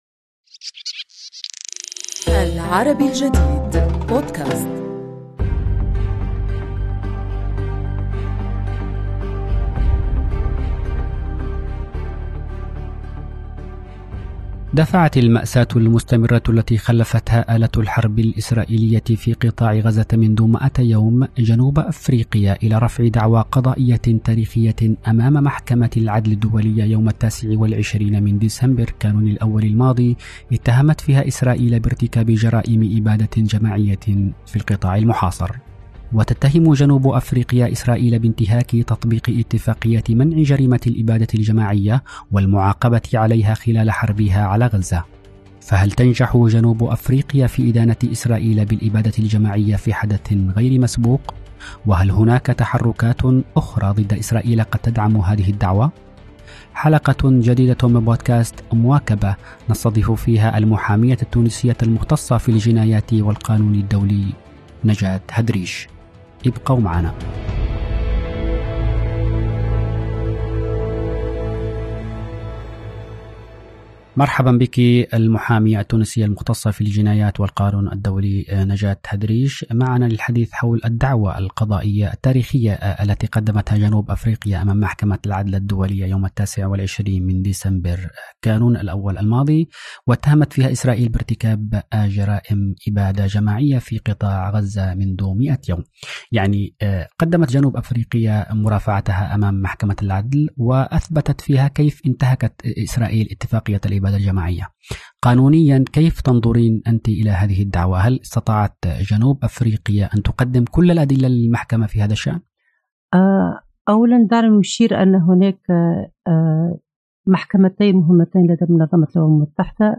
حلقة جديدة من "بودكاست مواكبة" نستضيف فيها المحامية التونسية المختصةَ في الجنايات والقانون الدّولي